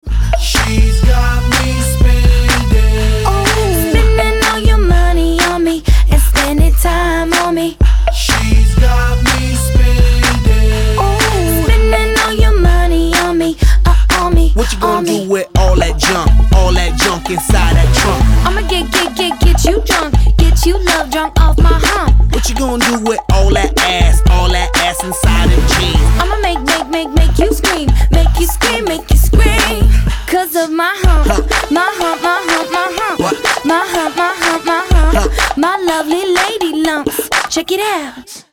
• Качество: 256, Stereo
красивый женский голос
Отрывок песни